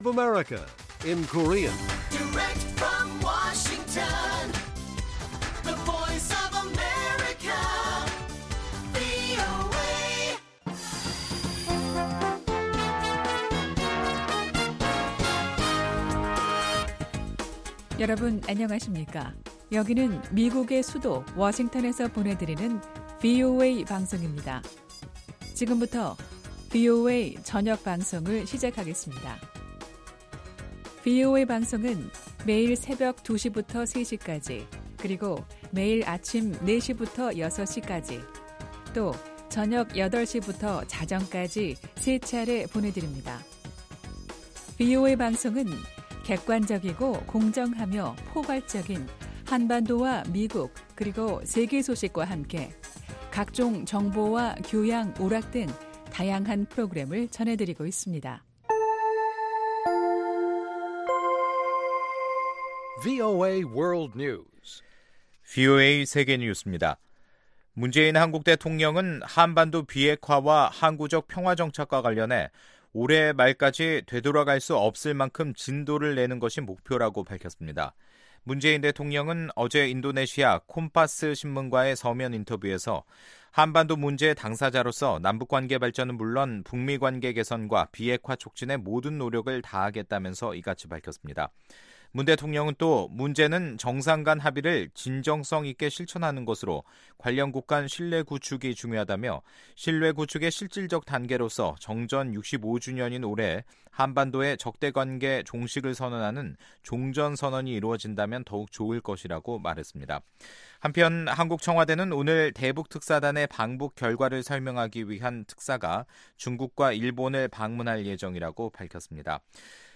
VOA 한국어 간판 뉴스 프로그램 '뉴스 투데이', 2018년 9월 7일 1부 방송입니다. 남북정상회담이 18-20일 평양에서 열립니다. 도널드 트럼프 미국 대통령이 자신에 대한 신뢰를 표명한 김정은 북한 국무위원장에게 감사의 뜻을 밝혔습니다. 미 국무부가 남북 철도 협력과 관련해 대북제재 이행을 강조했습니다.